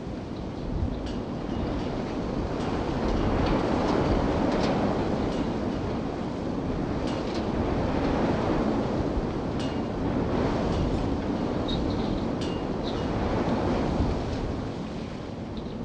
wind_city_1.ogg